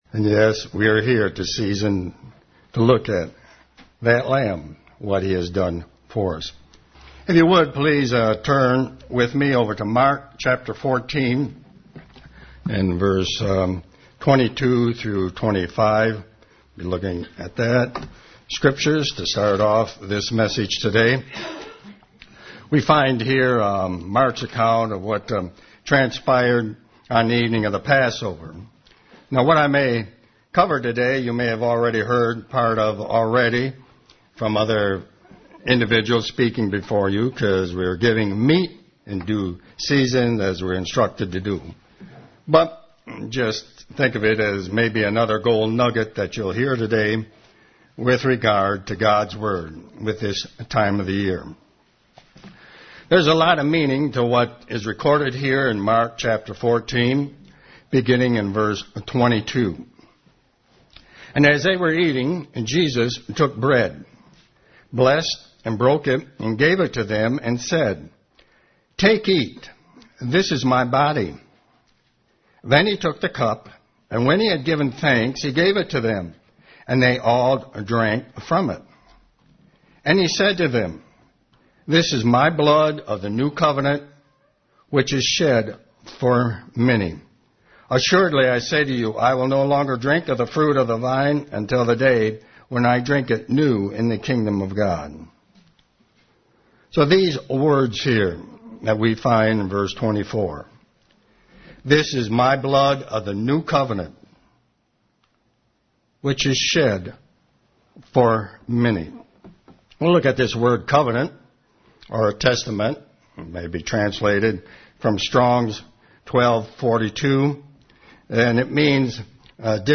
Sermon
Given in Ann Arbor, MI